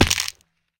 fallbig1.ogg